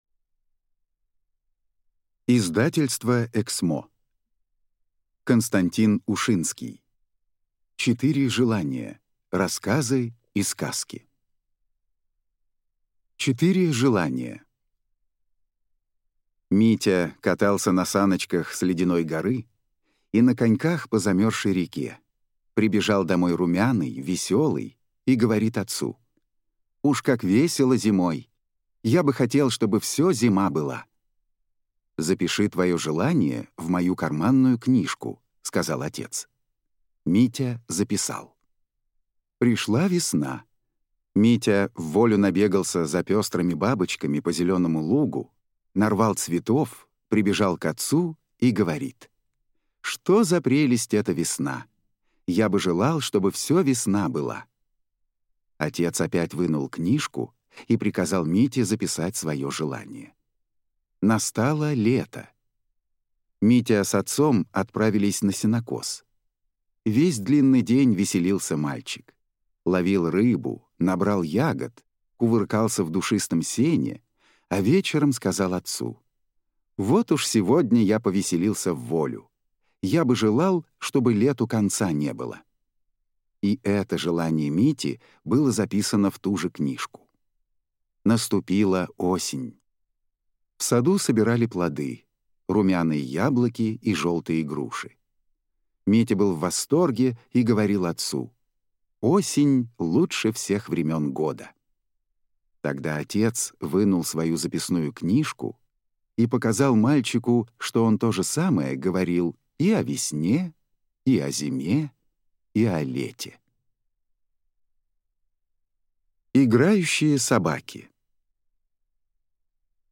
Аудиокнига Четыре желания. Рассказы и сказки (ил.